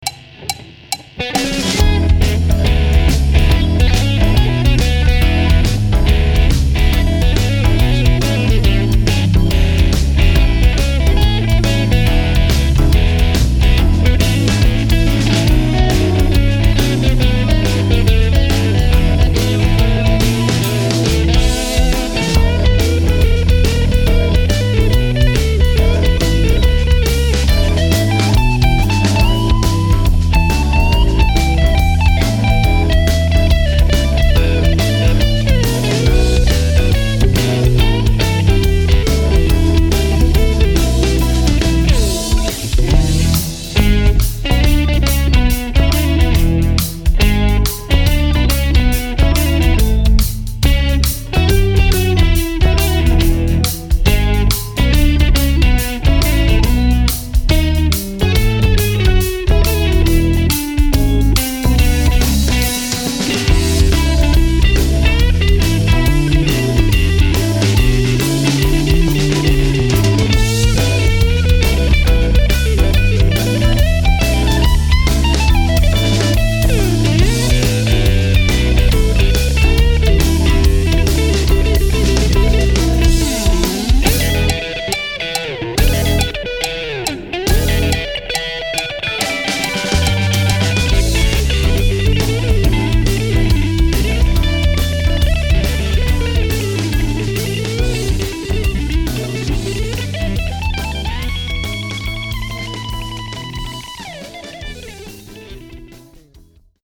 mit Keyboard, E-Giitarre etc. - Authentisch - Spitzensound
• Coverband
ROCK sologit